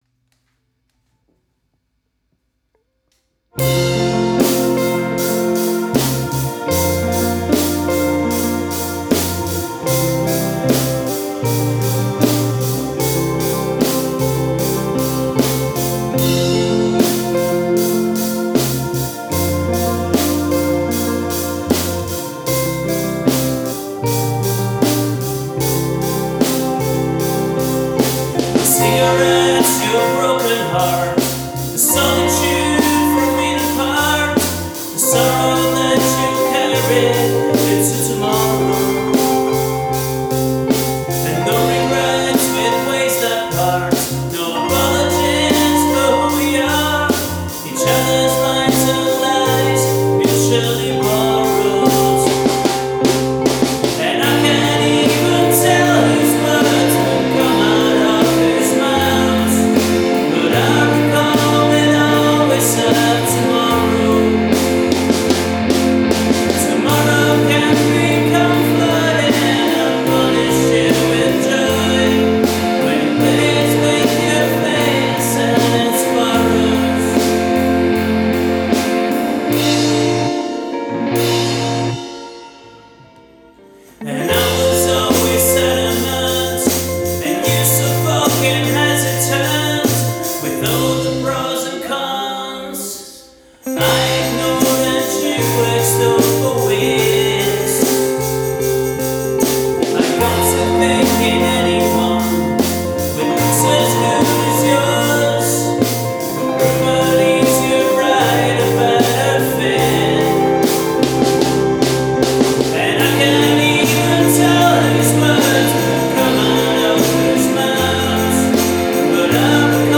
vocals, guitars, bass, drums, keyboards, percussion